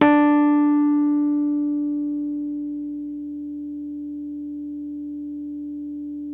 RHODES CL0BR.wav